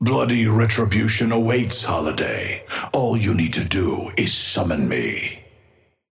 Amber Hand voice line - Bloody retribution awaits, Holliday. All you need to do is summon me.
Patron_male_ally_astro_start_04.mp3